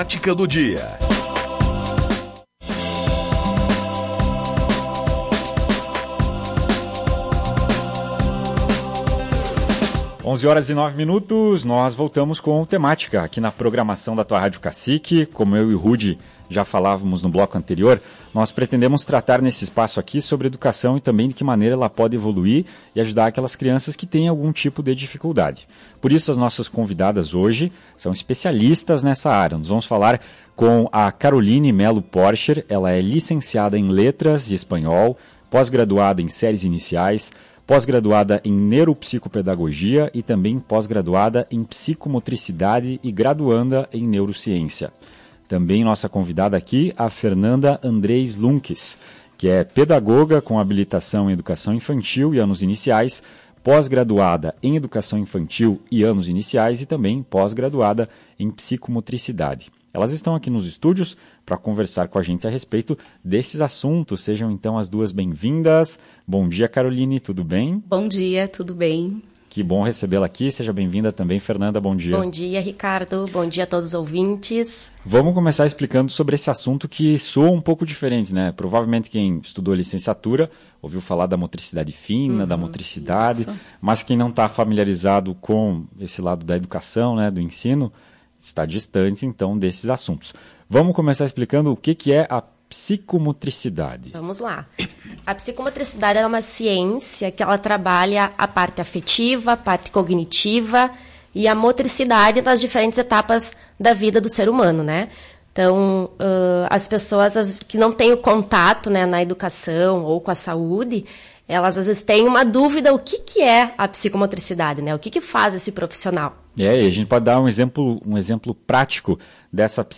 O programa Temática da manhã desta segunda-feira, 01 de agosto, recebeu duas profissionais que utilizam dos conhecimentos em neuropsicopedagogia e psicomotricidade para auxiliar as crianças a se desenvolverem e melhorarem suas habilidades de aprendizado.